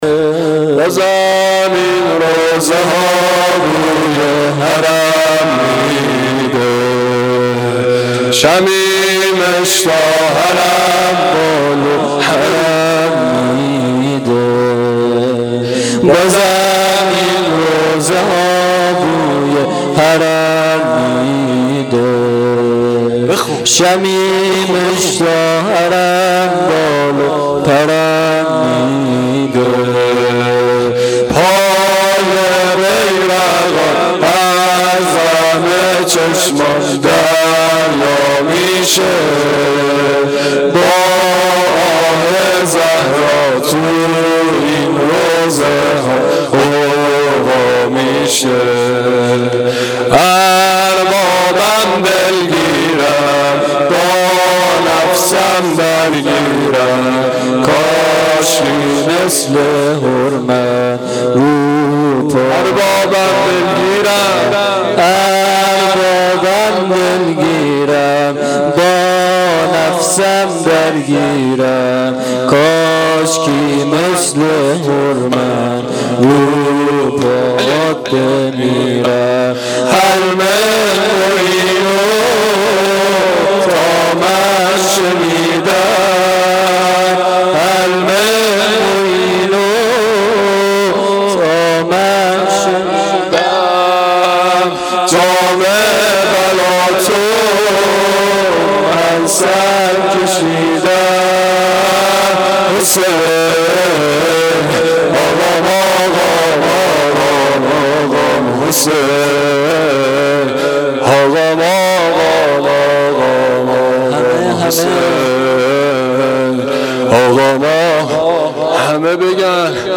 دم پایانی شب دوم محرم الحرام 1395